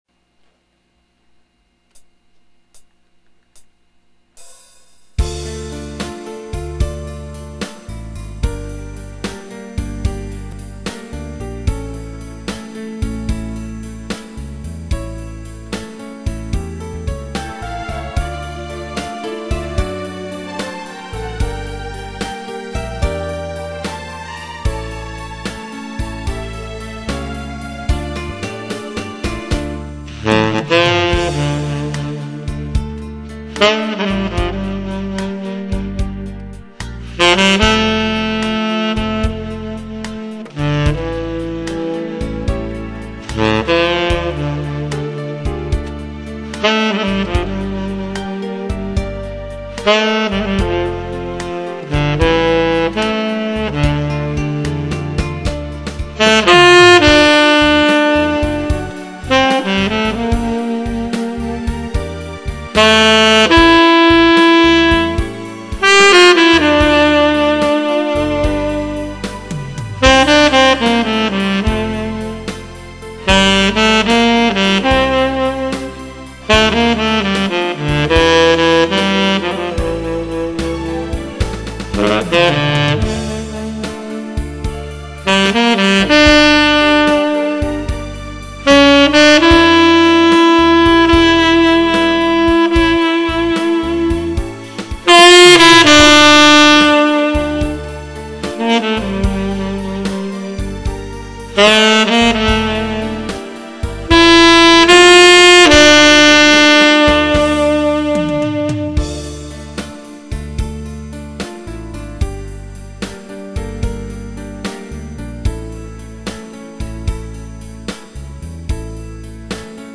색소폰으로 잘불면 멋진노랜데....